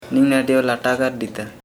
Dialect: Hill Remo